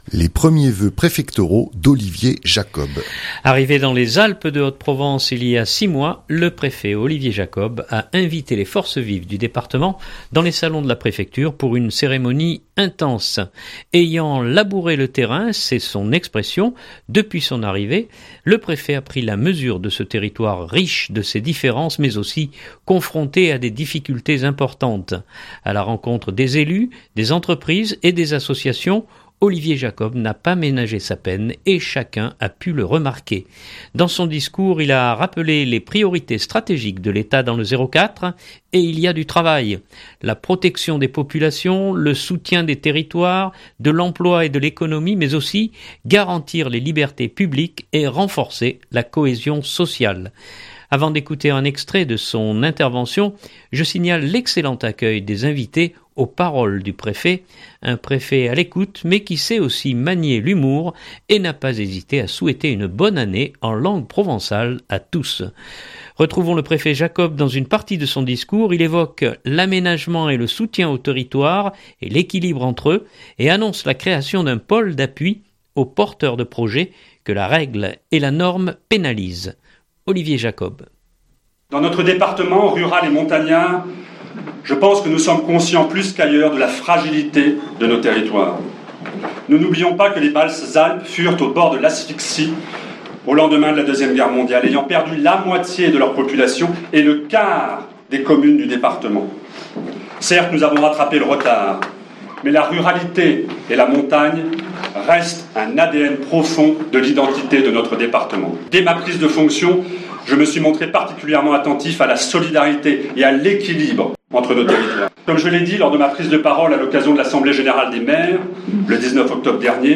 2019-01-15-Voeux Olivier Jacob (2.17 Mo) Arrivé dans les Alpes de Haute-Provence il y a 6 mois, le préfet Olivier Jacob a invité les forces vives du département dans les salons de la Préfecture pour une cérémonie intense.
Retrouvons le préfet Jacob dans une partie de son discours. Il évoque l’aménagement et le soutien aux territoires et l’équilibre entre eux et annonce la création d’un pôle d’appui aux porteurs de projets que la règle et la norme pénalisent.